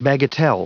Prononciation du mot bagatelle en anglais (fichier audio)
Prononciation du mot : bagatelle